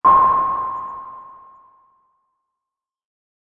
sonar-89448.mp3